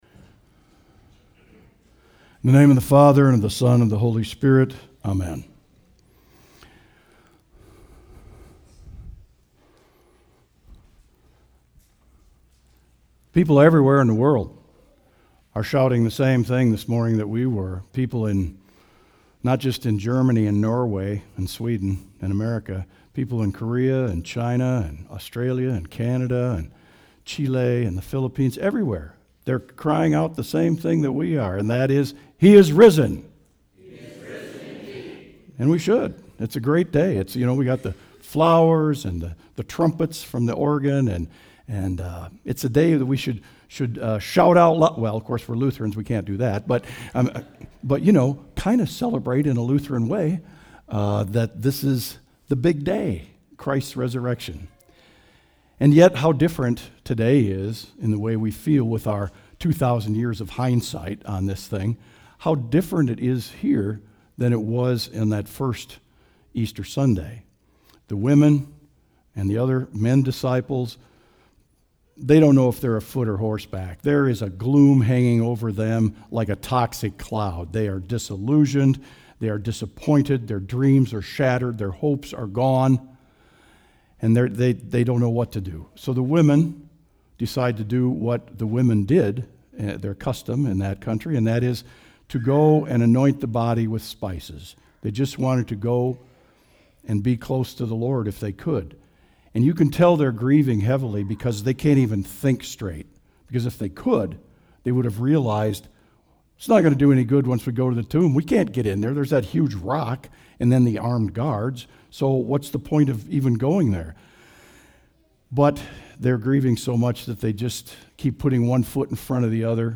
Audio Sermon “Living In Hope”